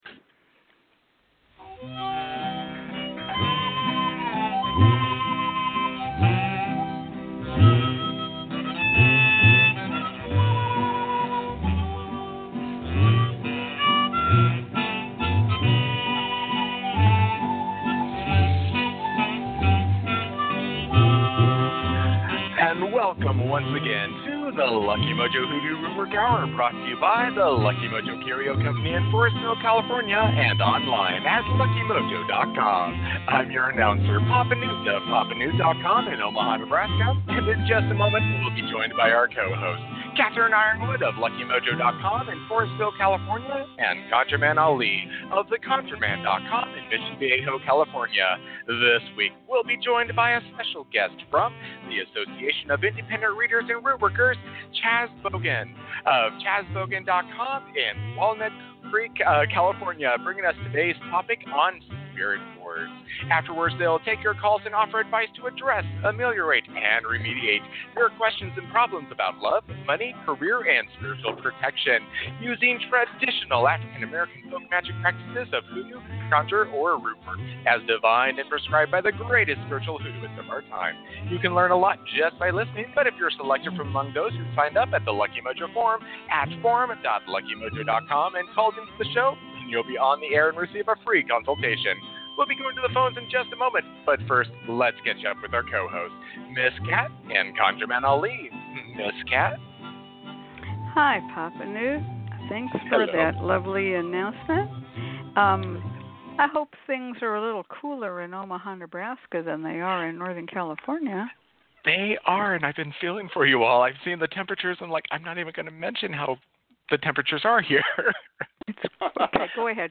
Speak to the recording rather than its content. During the show when you call, press '1' in order to 'raise your hand' appear on the show and receive a free reading and consultation.